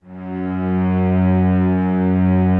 Index of /90_sSampleCDs/Optical Media International - Sonic Images Library/SI1_Swell String/SI1_Octaves